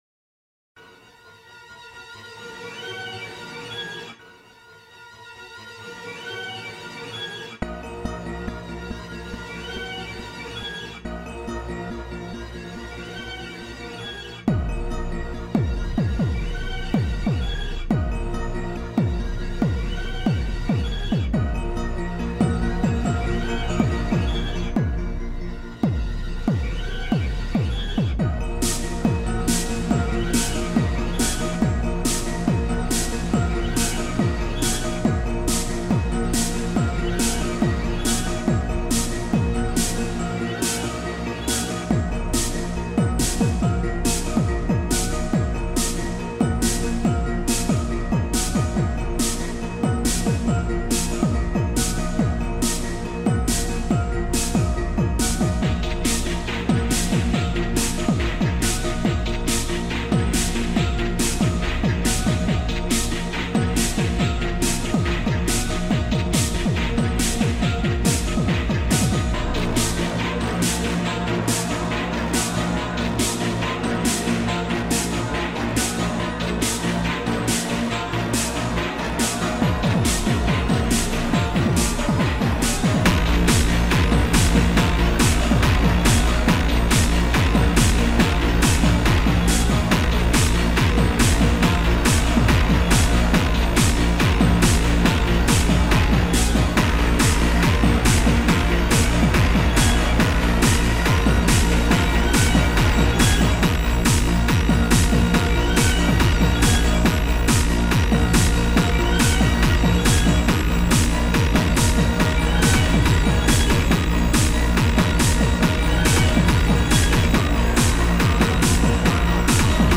[EBM]
Non, c'est pas de la trance, c'est pas psyché non plus, mais y'en a qui aiment, alors pourquoi pas vous ?
J'adore l'entrée du kick volcanique
Et les vocaux trash sont plus que bienvenus...
Ya un coté "dramatique" bien foutu
la voix trash est samplée sur un morceau du groupe norvégien Immortal - un pilier du black metal.